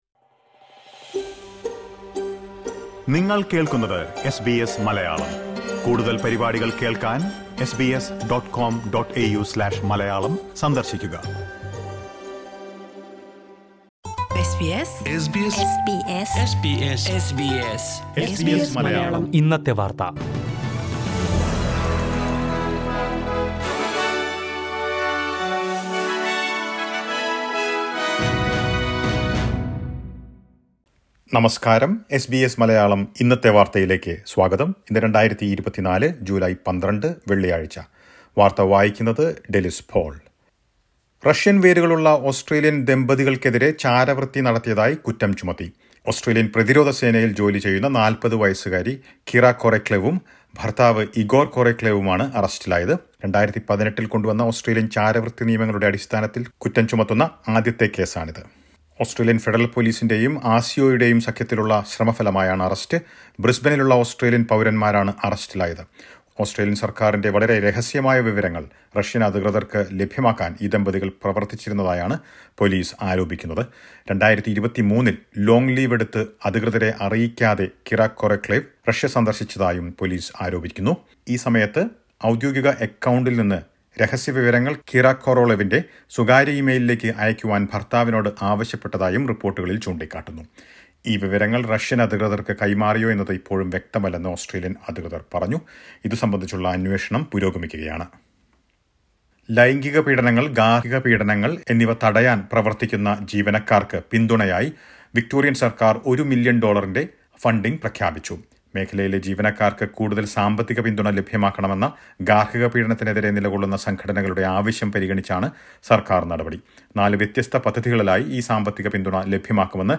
2024 ജൂലൈ 12ലെ ഓസ്‌ട്രേലിയയിലെ ഏറ്റവും പ്രധാന വാര്‍ത്തകള്‍ കേള്‍ക്കാം...